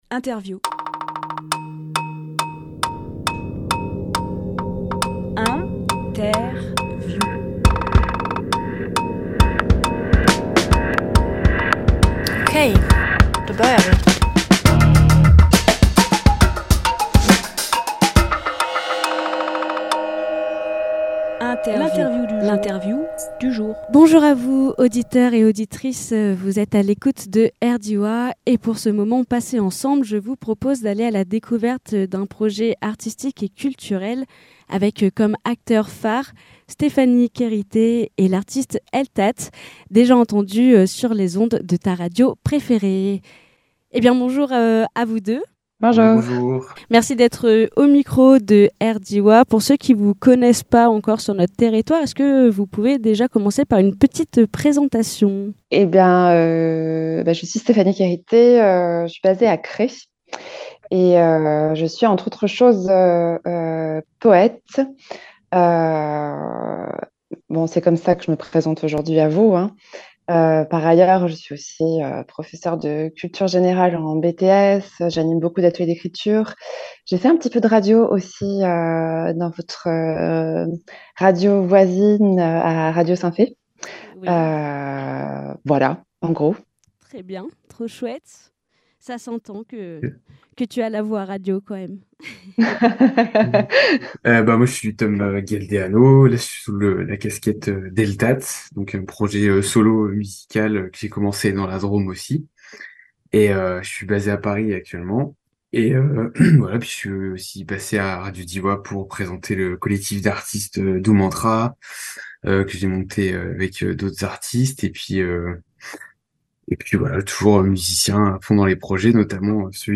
Emission - Interview L’autre en Travers Publié le 9 février 2024 Partager sur…
Lieu : Studio de Luc-en-Diois – Interview via Zoom (invités en distanciel.)